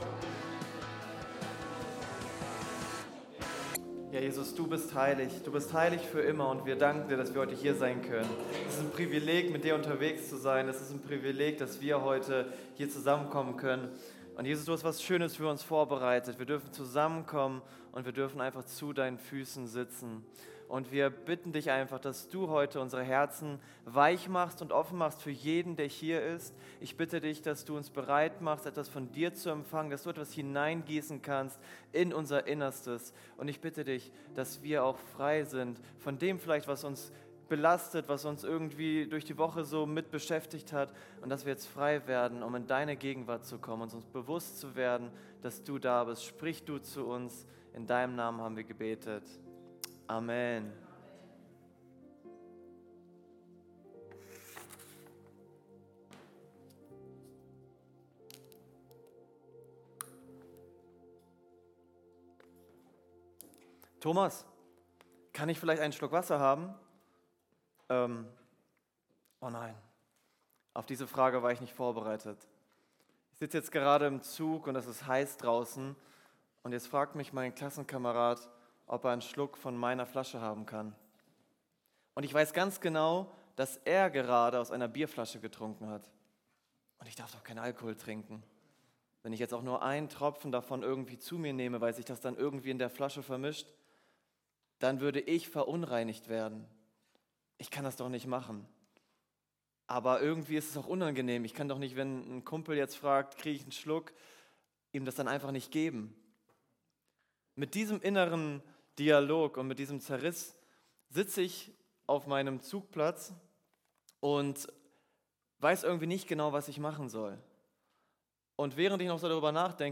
Kirche am Ostbahnhof Navigation Infos Über uns…